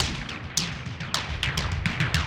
Index of /musicradar/rhythmic-inspiration-samples/105bpm